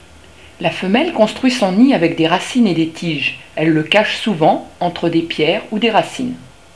Le rouge gorge
Il chante pour séduire la femelle.